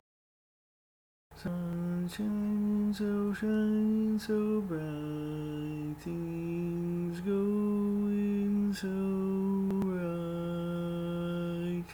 Key written in: F Major
Each recording below is single part only.